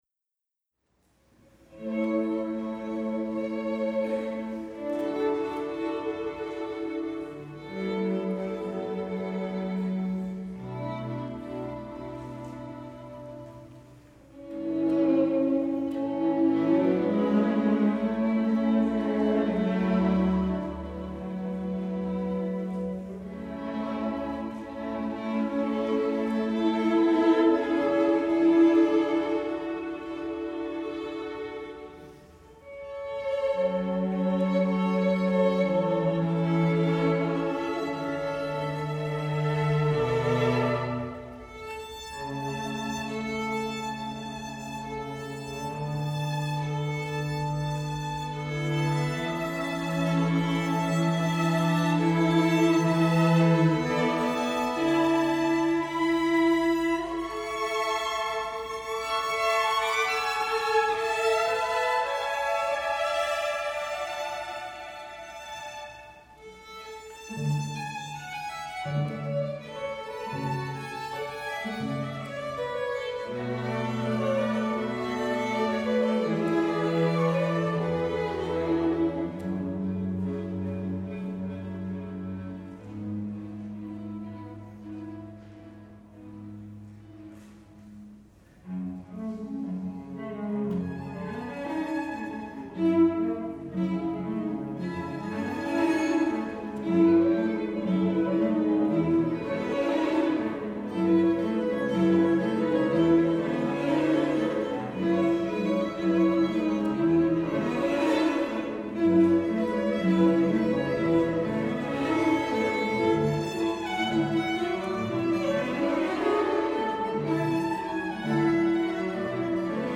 for String Quartet (2014)
The ending is once again serene.